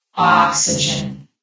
S.P.L.U.R.T-Station-13/sound/vox_fem/oxygen.ogg
* New & Fixed AI VOX Sound Files